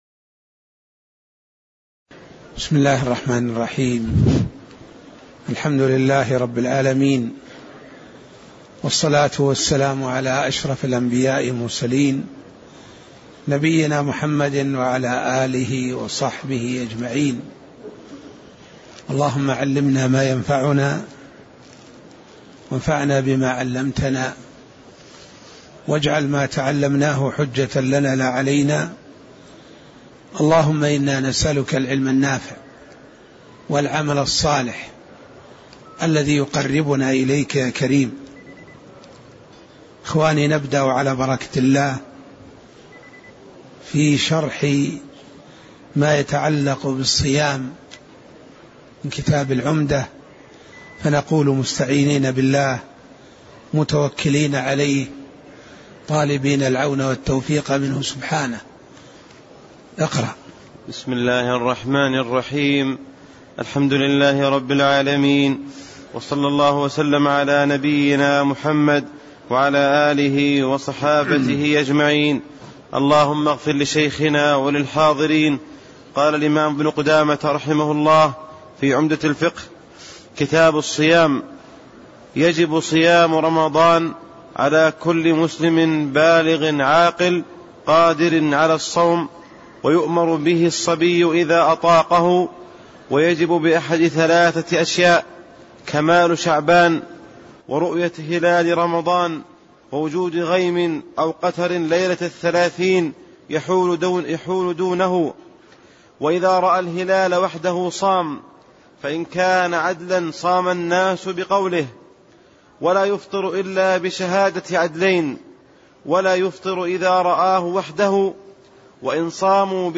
تاريخ النشر ٥ شعبان ١٤٣٤ هـ المكان: المسجد النبوي الشيخ